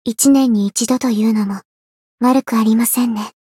灵魂潮汐-阿卡赛特-七夕（摸头语音）.ogg